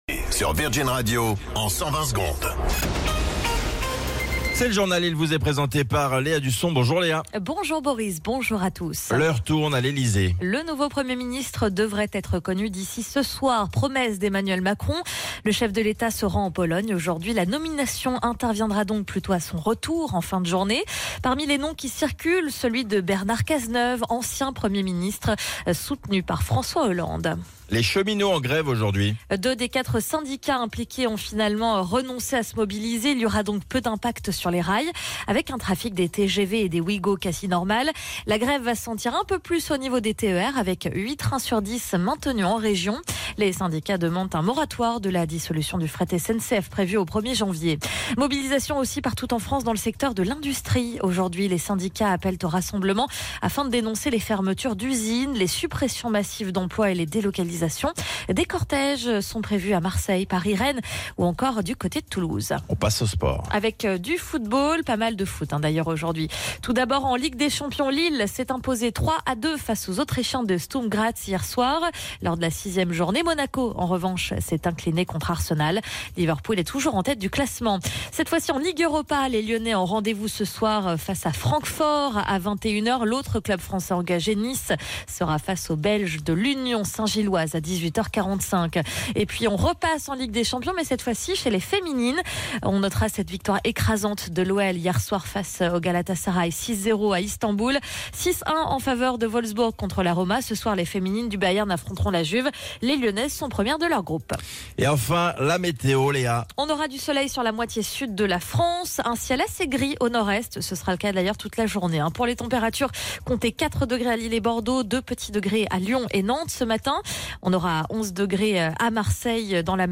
Flash Info National 12 Décembre 2024 Du 12/12/2024 à 07h10 .